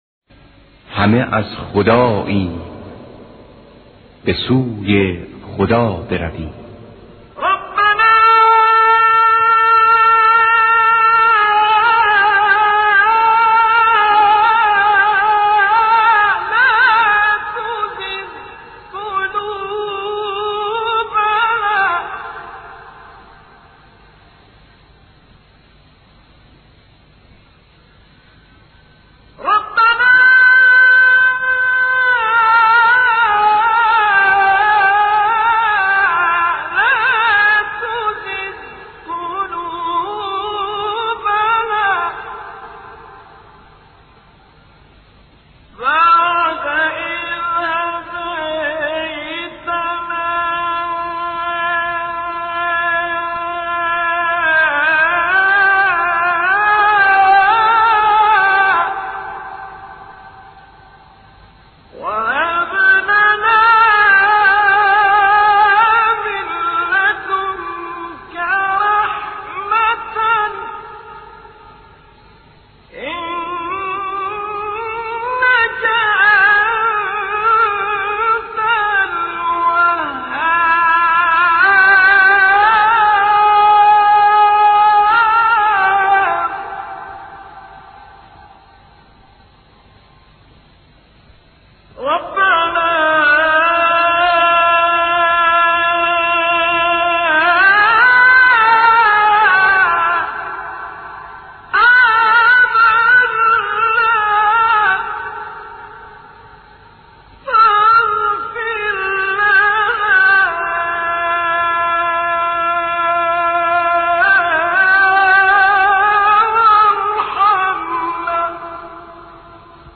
دستگاه سه‌گاه
آواز‌های افشاری و گوشه عراق